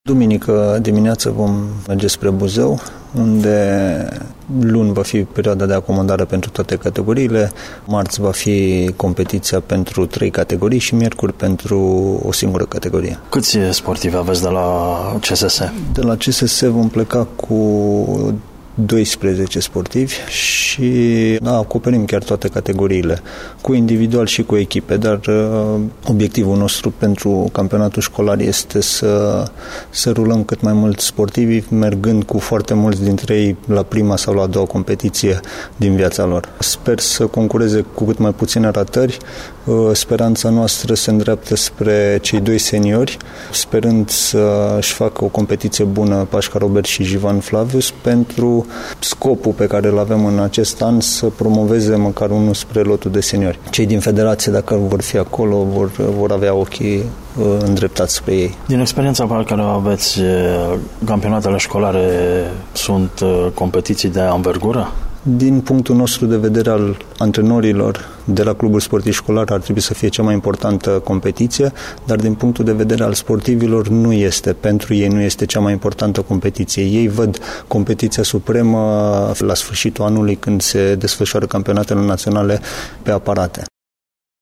Ascultați declarația antrenorului